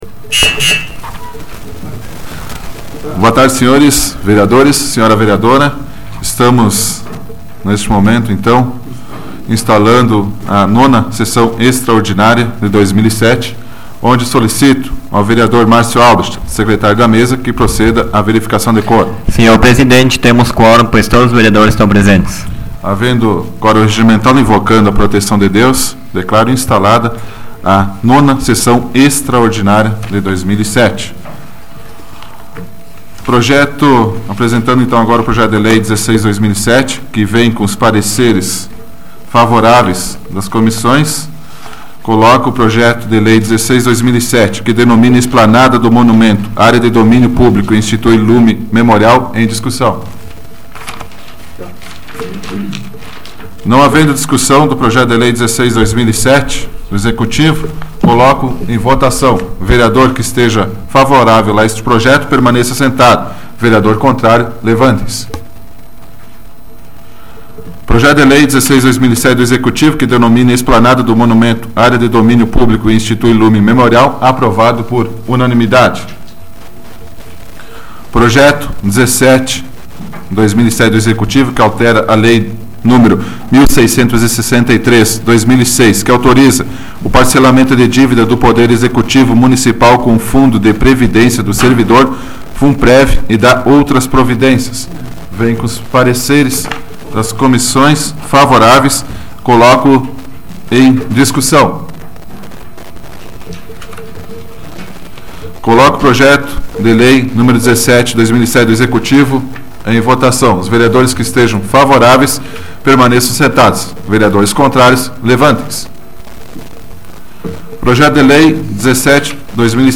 Áudio da 38ª Sessão Plenária Extraordinária da 12ª Legislatura, de 23 de julho de 2007